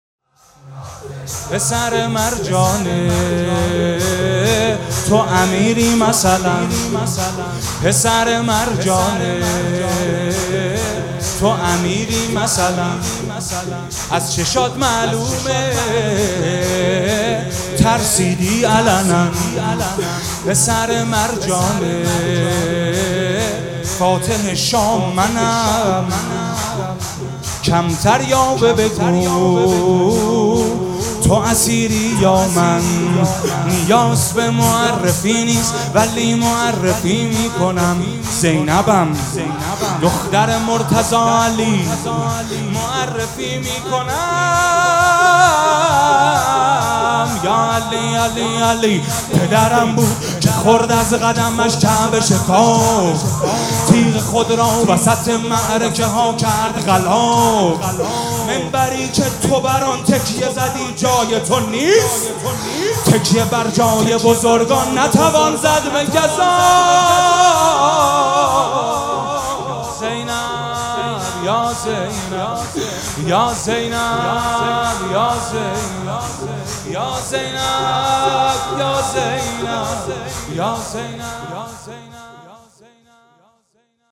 پخش آنلاین نوحه